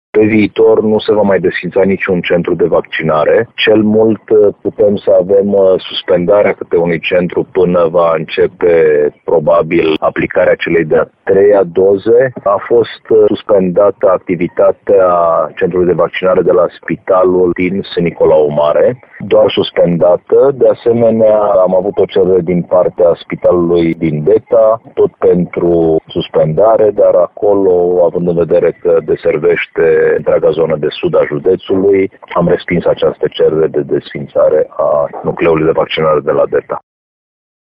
Subprefectul Ovidiu Drăgănescu a mai precizat că, în săptămânile următoare, vor fi ultimele deplaspări la sate pentru rapel, ultimele doze urmând să fie administrate în Dudeștii Noi și Orțișoara.